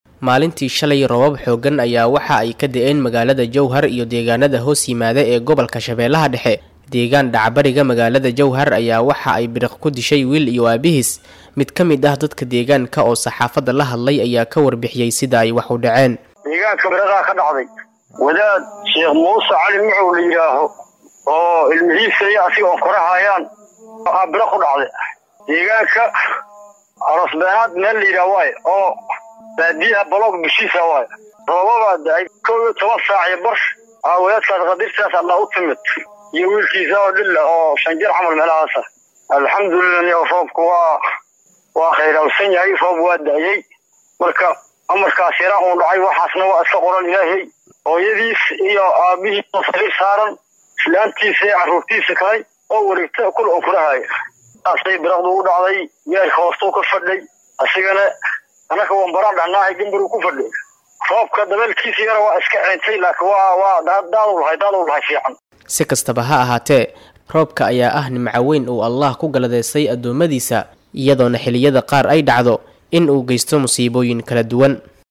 Mid kamid ah dadka deegaanka oo saxaafadda la hadlay ayaa ka warbixiyay sida ay wax udhaceen.